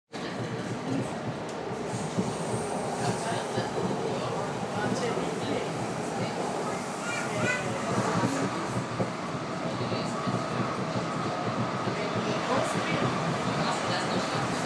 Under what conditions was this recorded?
In der UBahn